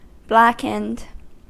Ääntäminen
Ääntäminen US Tuntematon aksentti: IPA : /ˈblæk.ənd/ Haettu sana löytyi näillä lähdekielillä: englanti Käännöksiä ei löytynyt valitulle kohdekielelle.